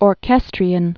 (ôr-kĕstrē-ən) also or·ches·tri·na (ôrkĭ-strēnə)